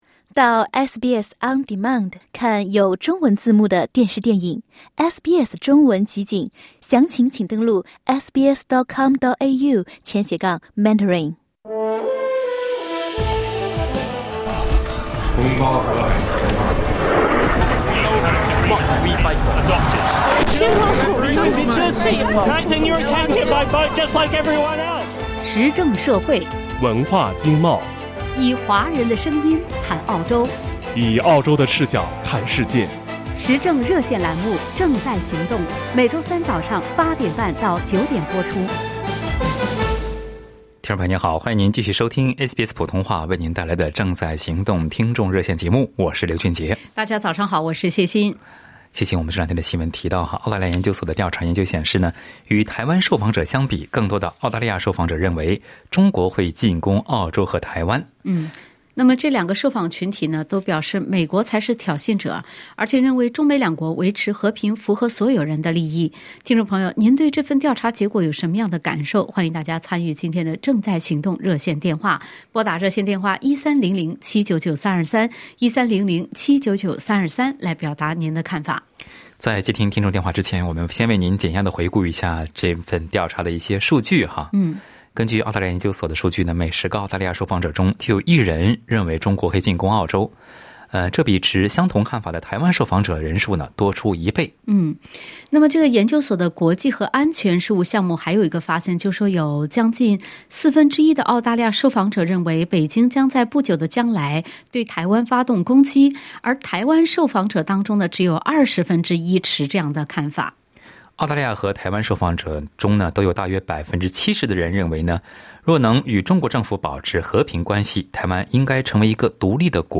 在本期《正在行动》听众热线节目中，就这份调查的结果，听友们从民调与政治风向的相关性、媒体的公正性和其内容的可信度、历史经验教训、公平正义、共情心等角度分享了感受和看法。